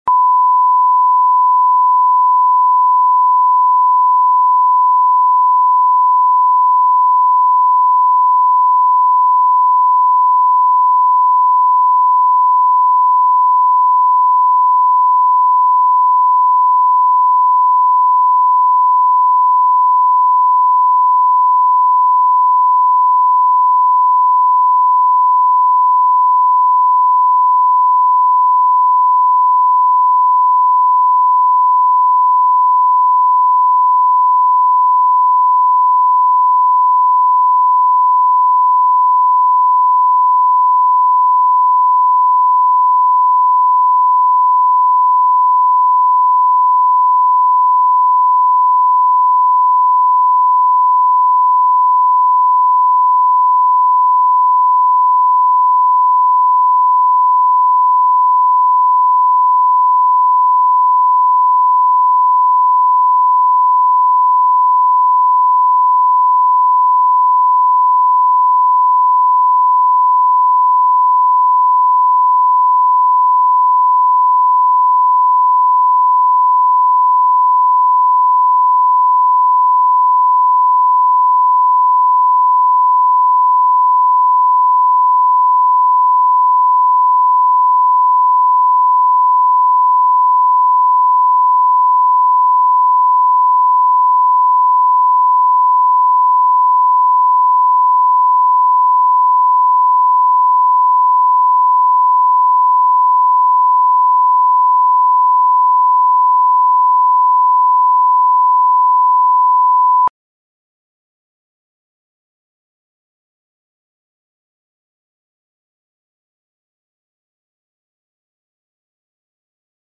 Аудиокнига Клеопатра